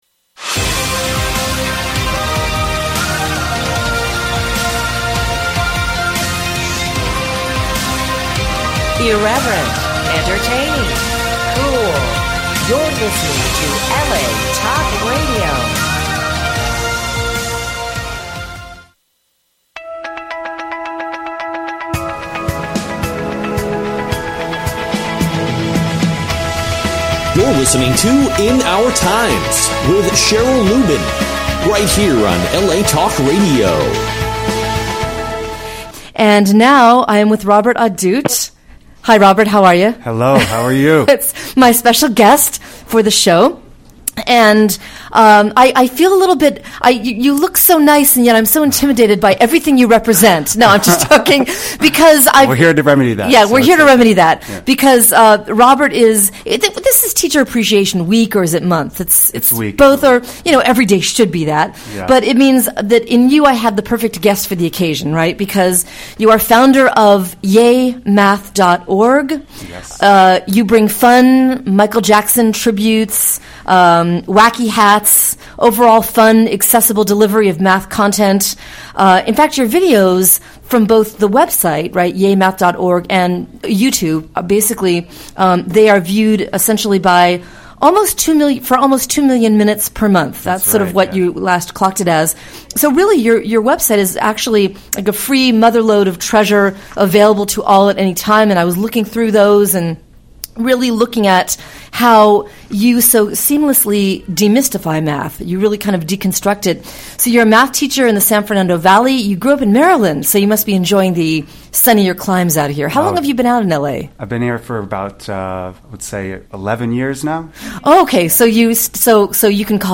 What a conversation!